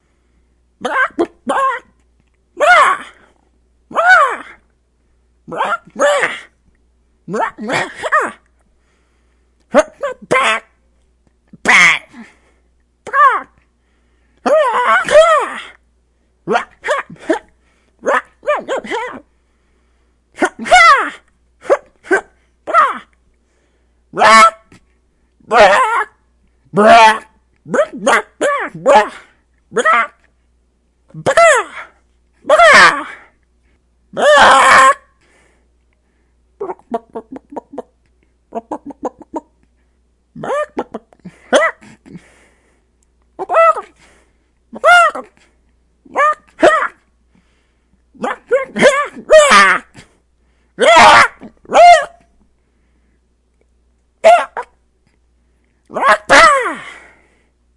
描述：一些声音代表了粉丝制作的Blaziken角色。打算用作战斗咕噜声。
Tag: 动物 模仿 男性 语音